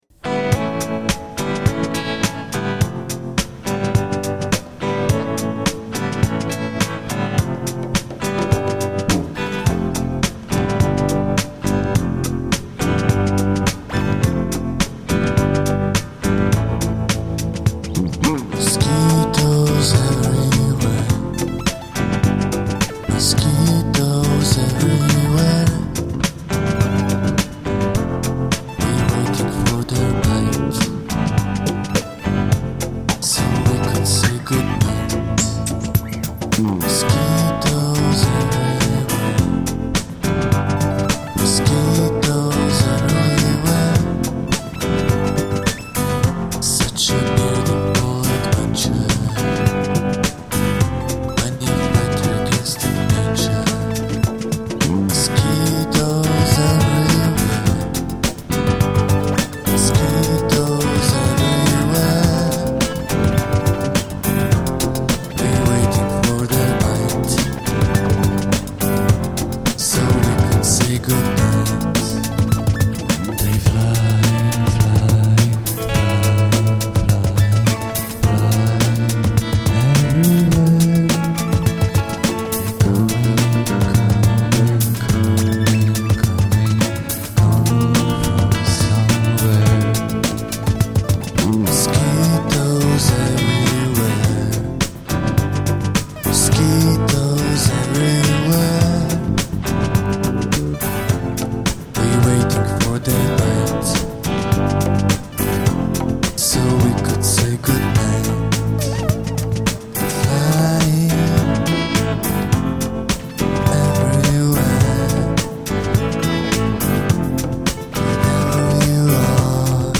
vokal, gitara
bubanj
glas